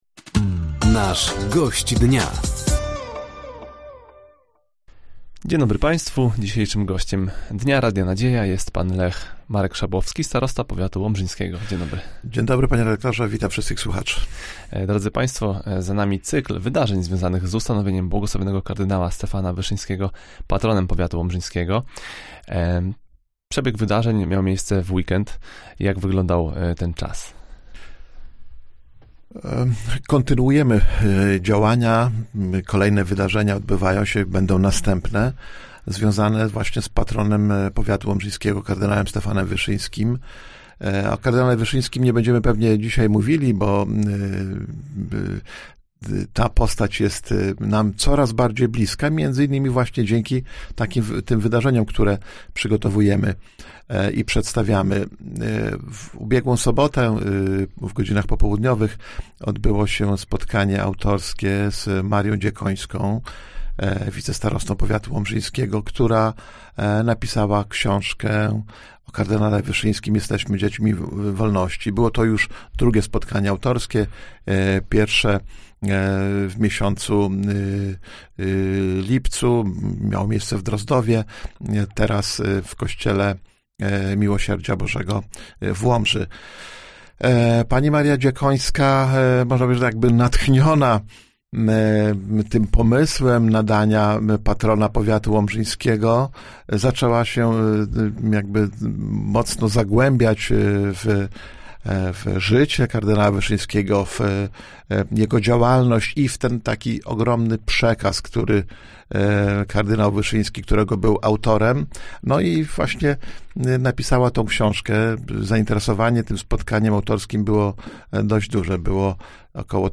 Gościem Dnia Radia Nadzieja był starosta powiatu łomżyńskiego, Lech Marek Szabłowski. Tematem rozmowy były między innymi wspomnienia wydarzeń związanych z ustanowieniem błogosławionego kardynała Stefana Wyszyńskiego Patronem Powiatu, inwestycje drogowe i prace zabytkowe.